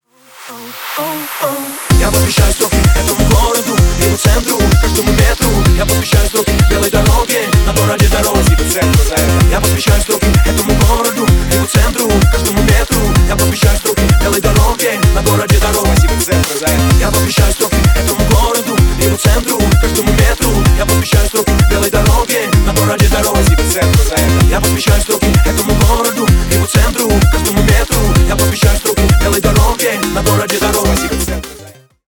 Ремикс # Рэп и Хип Хоп
ритмичные